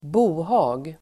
Ladda ner uttalet
Uttal: [b'o:ha:g]